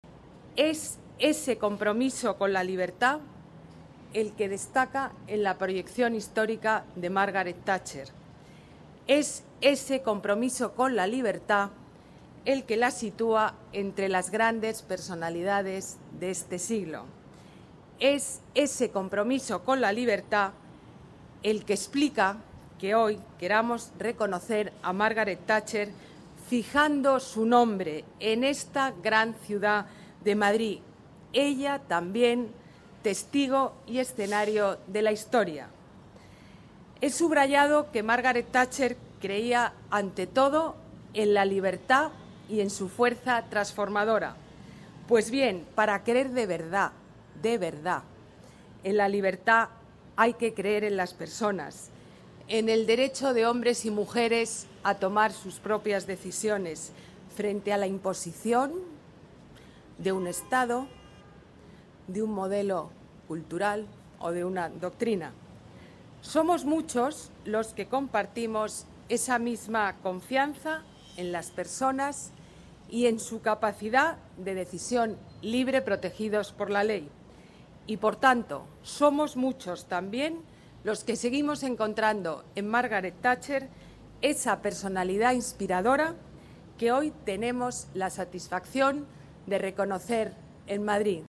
Nueva ventana:Declaraciones de la alcaldesa Ana Botella: Plaza Margaret Thatcher